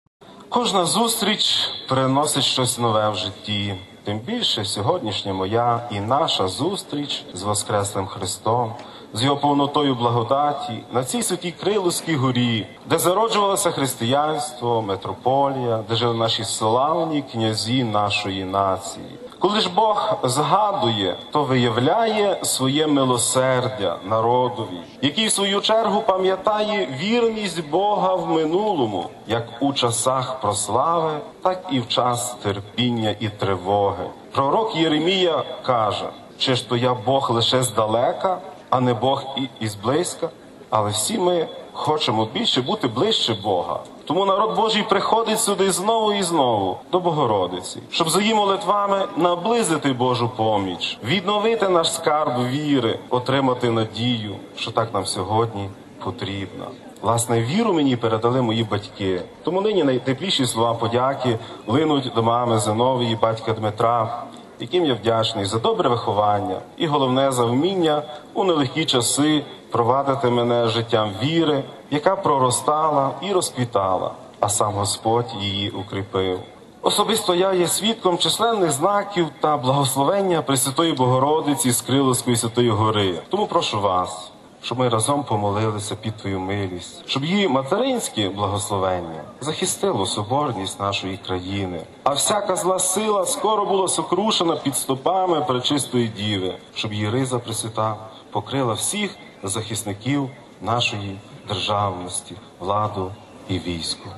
Блаженніший Святослав звернувся до нового співбрата в єпископстві та прочан з такими словами: RealAudio